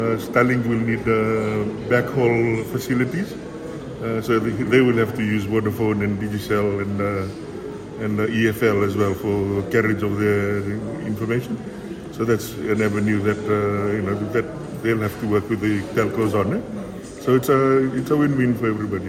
Communications Minister Manoa Kamikamica